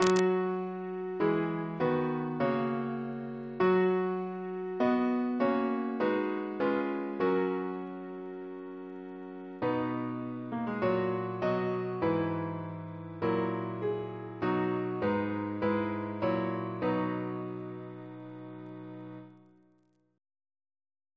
Double chant in B Composer: Chris Biemesderfer (b.1958)